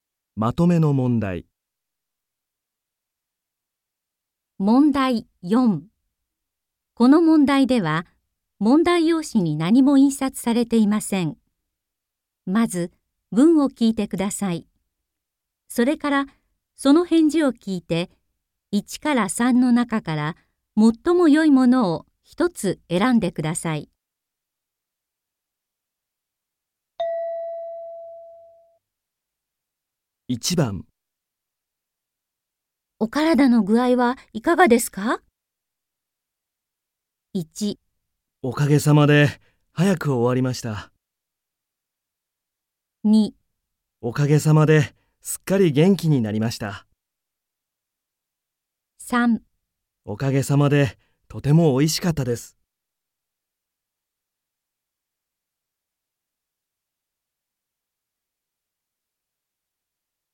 問題4 ［聴解］